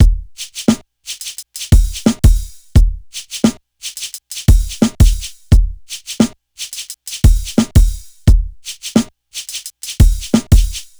• 87 Bpm Drum Loop Sample E Key.wav
Free drum loop sample - kick tuned to the E note. Loudest frequency: 978Hz
87-bpm-drum-loop-sample-e-key-DlT.wav